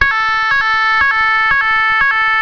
AVISADOR ACÚSTICO Y VOCAL - 1 MENSAJE
Tono 01 - Bitonal 554-440Hz